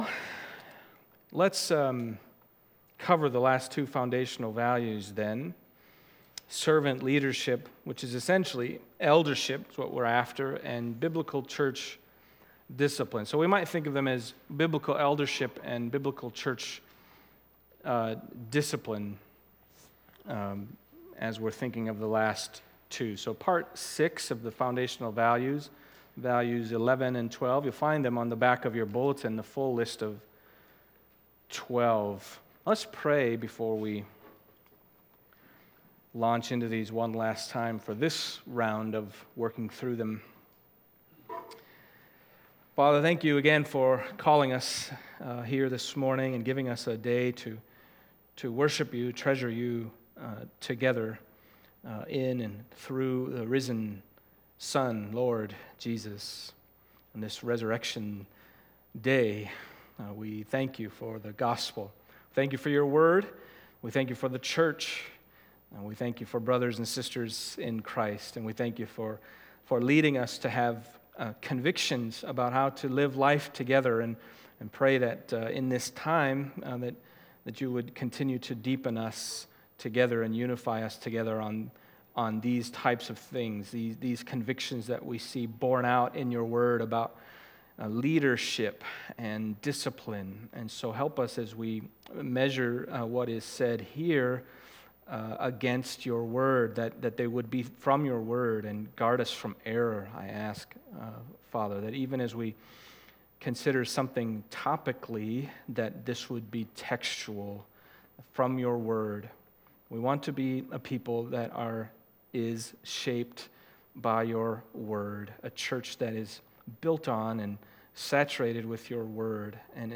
Foundational Values Service Type: Sunday Morning 11.